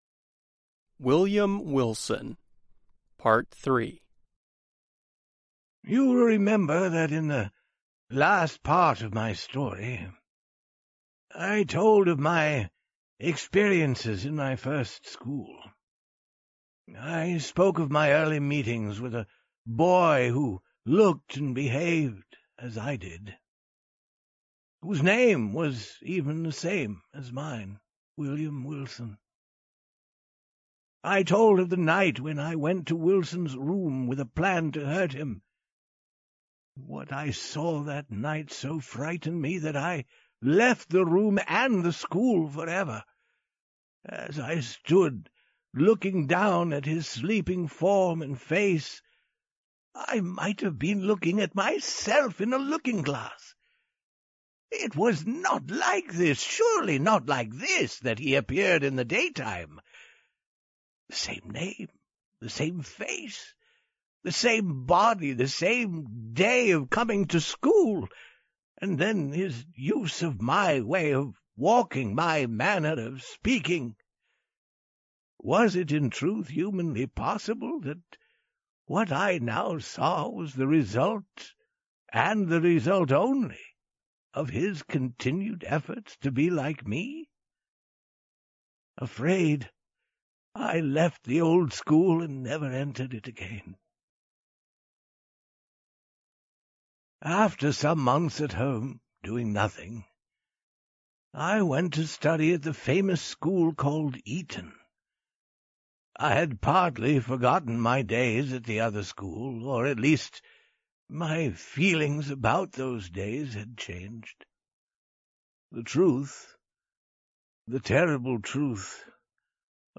We present the third of four parts of the short story "William Wilson," by Edgar Allan Poe. The story was originally adapted and recorded by the U.S. Department of State.